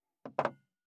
237,机に物を置く,テーブル等に物を置く,食器,グラス,コップ,工具,小物,雑貨,コトン,トン,ゴト,ポン,ガシャン,
コップ効果音厨房/台所/レストラン/kitchen物を置く食器